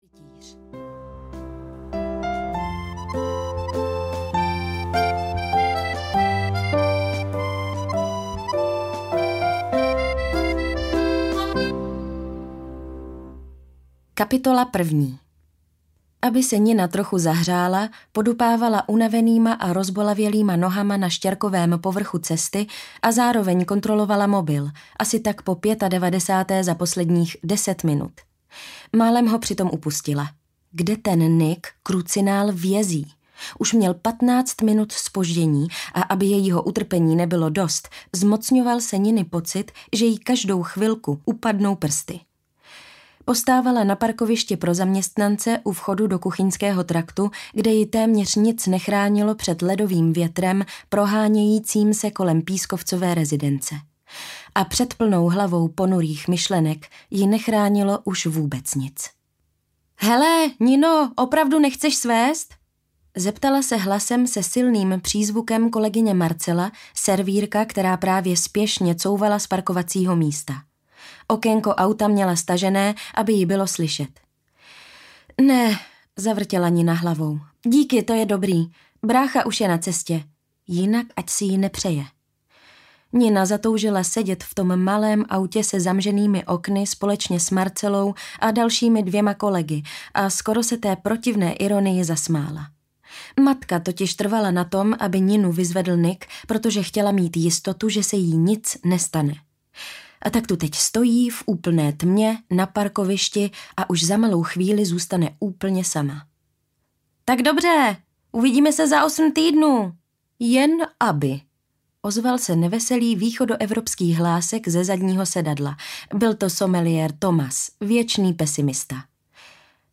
Cukrárna v Paříži audiokniha
Ukázka z knihy